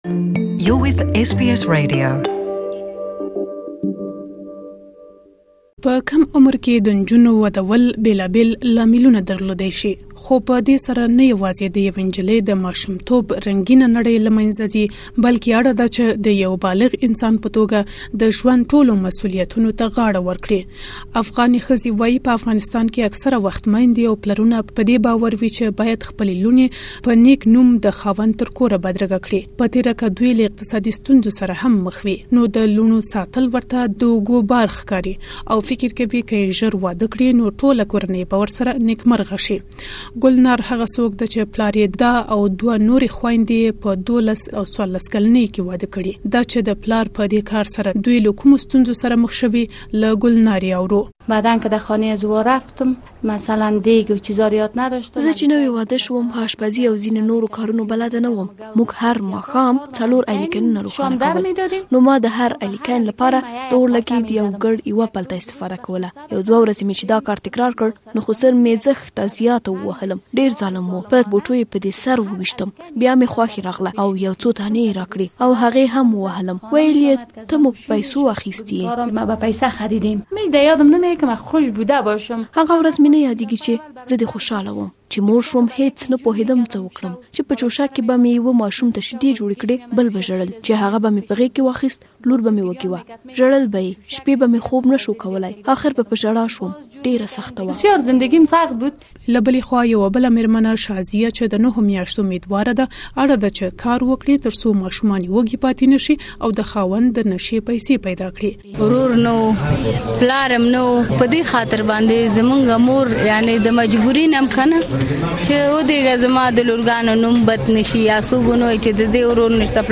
To know more, please listen to the full report in the Pashto language.